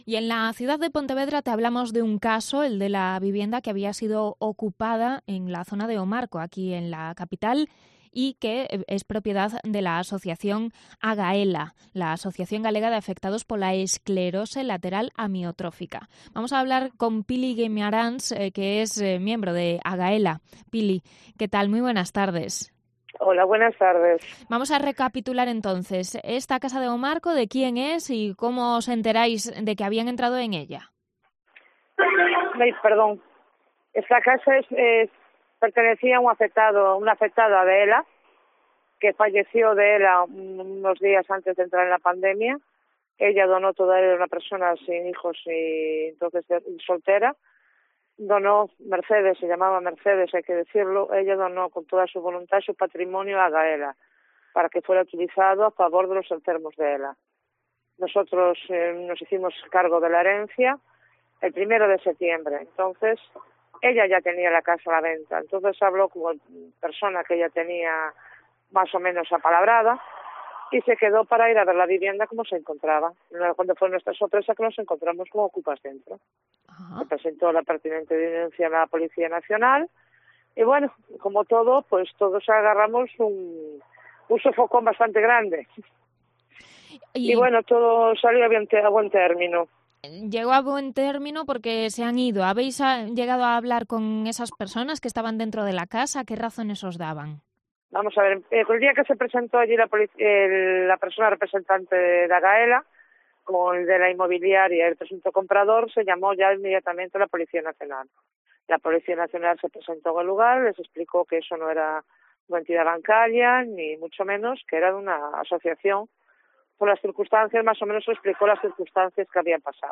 Entrevista sobre la vivienda okupada en Pontevedra perteneciente a AGAELA